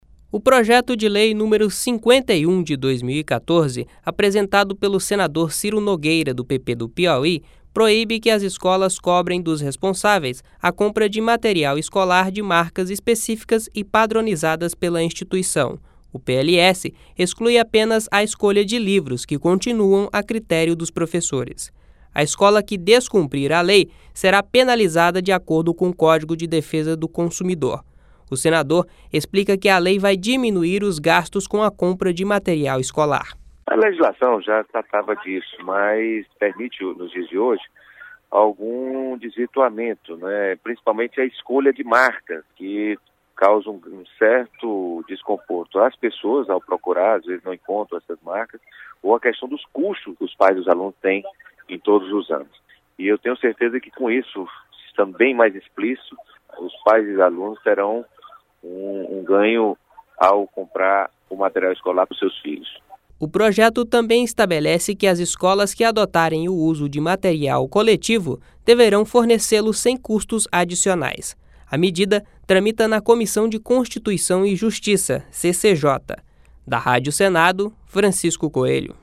(Repórter)